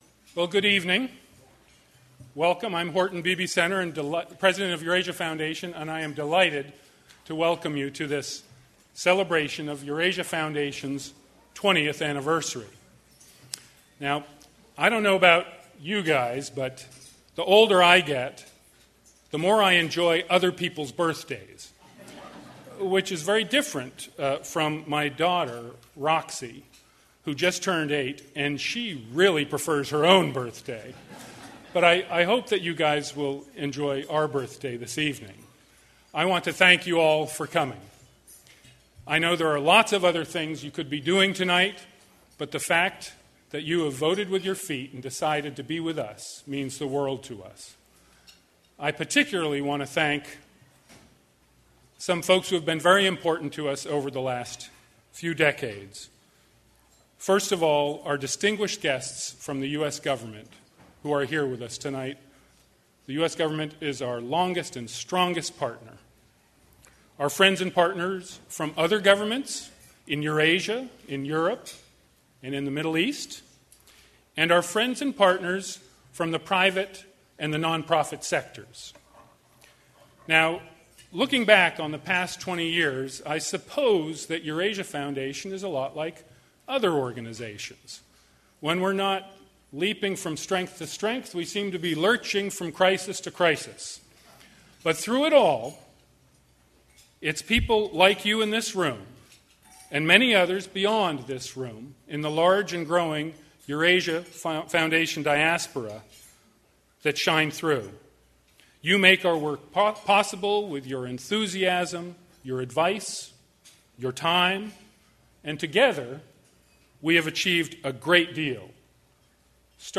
Eurasia Foundation - 20 years/Audio from gala in Washington, May 9 2013